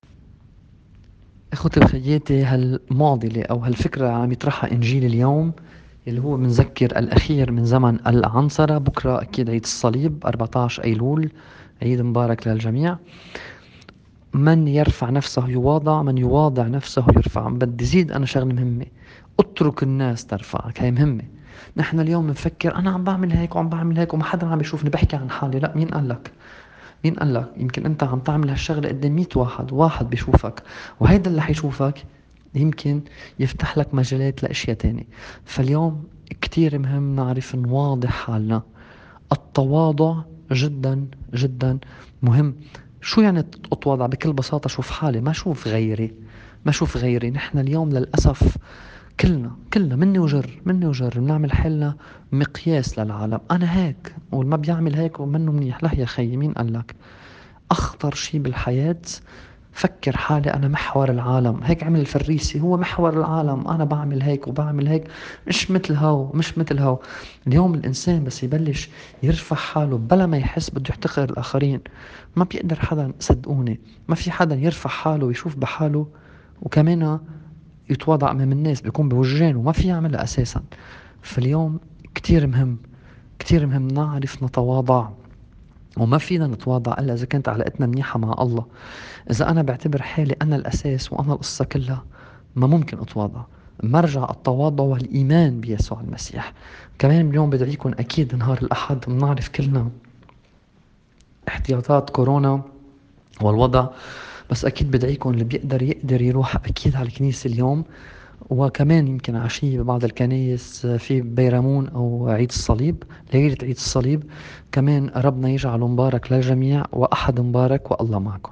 تأمّل في إنجيل يوم ١٣ أيلول ٢٠٢٠.mp3